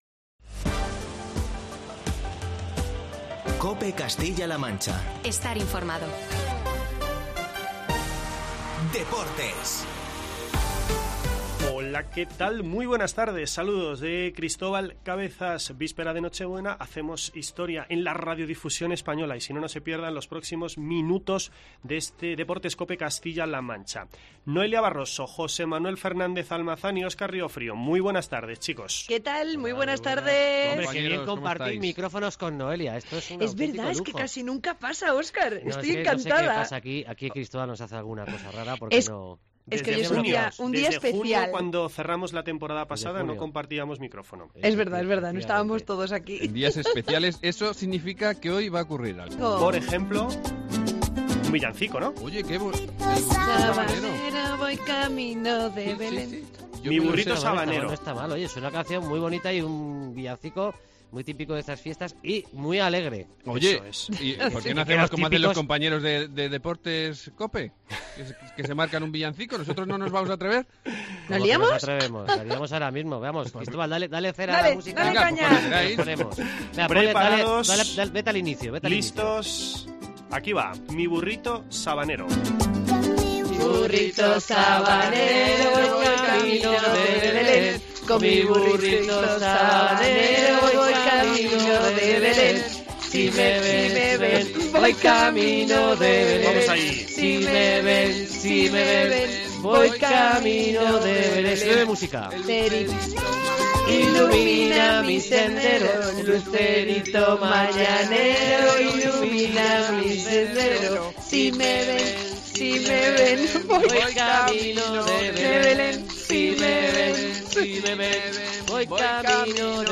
tradicional villancico venezolano